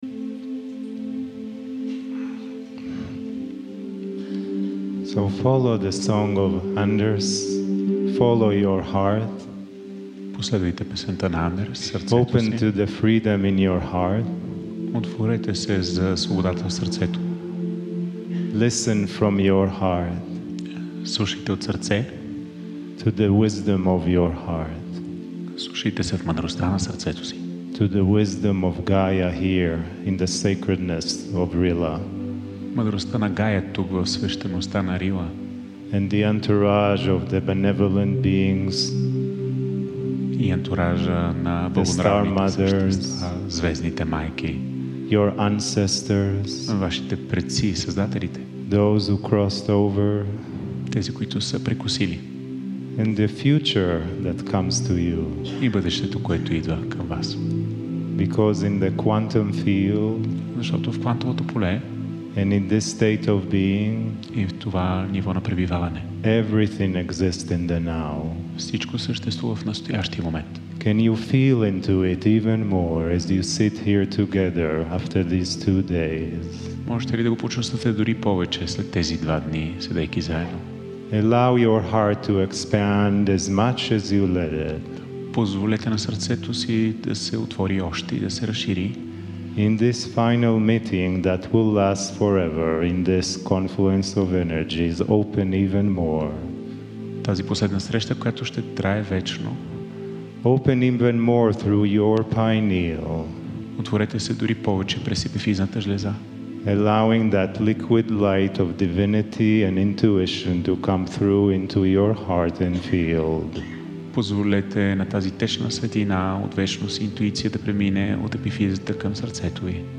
Kryon in Burgaria - September 14-15, 2019
KRYON-CHANNELLING For the Bulgarians